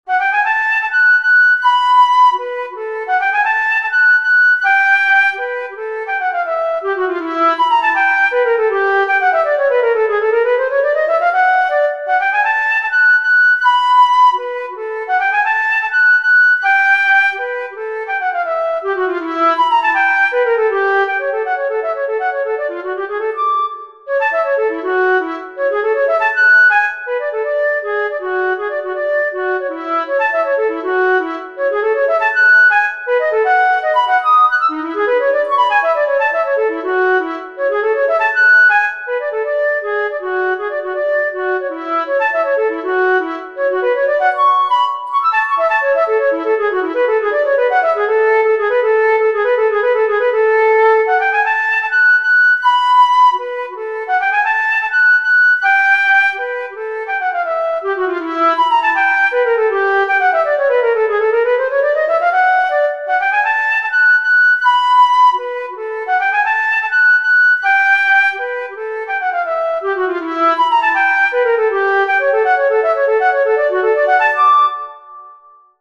Allegro moderato
Ütemmutató: 2/4 Tempo: 80 bpm
Előadói apparátus: szóló fuvola